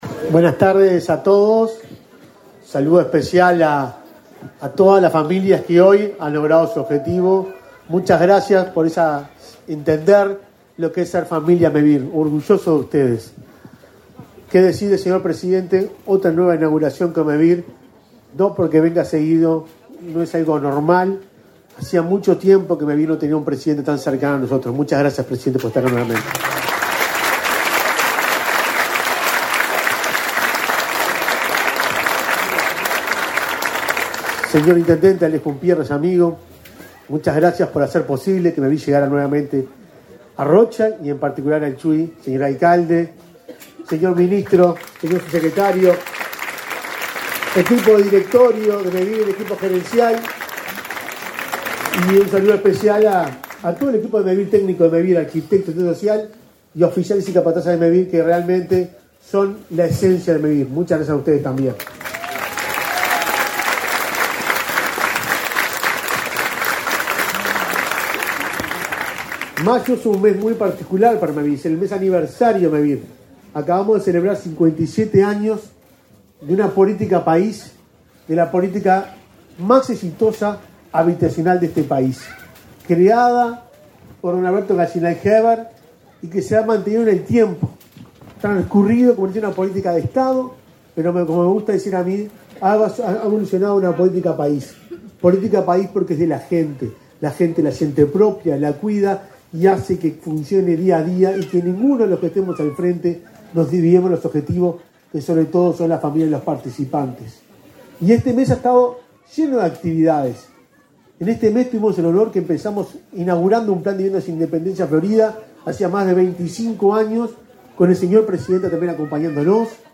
Acto de inauguración de viviendas de Mevir en Chuy
Acto de inauguración de viviendas de Mevir en Chuy 24/05/2024 Compartir Facebook X Copiar enlace WhatsApp LinkedIn Mevir inauguró, este 24 de mayo, soluciones habitacionales en Chuy, en el departamento de Rocha, con la presencia del presidente de la República, Luis Lacalle Pou. En el evento participaron el ministro de Vivienda, Raúl Lozano, y el presidente de Mevir, Juan Pablo Delgado.